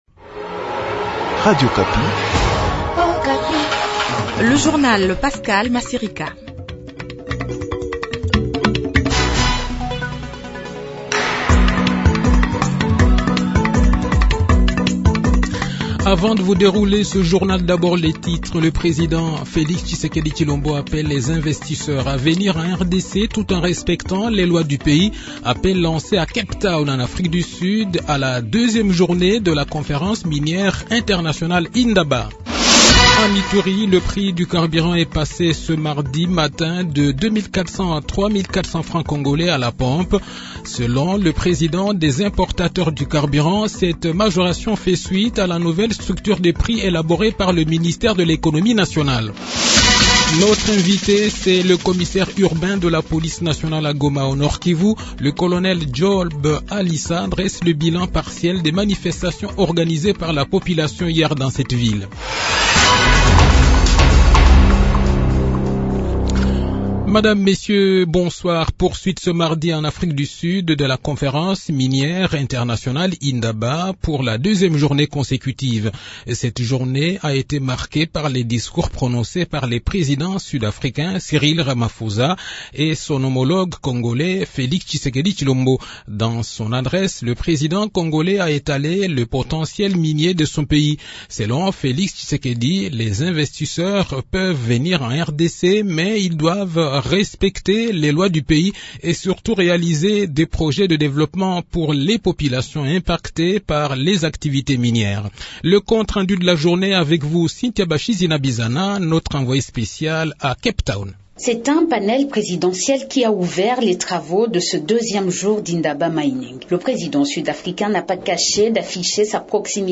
Le journal de 18 h, 7 fevrier 2023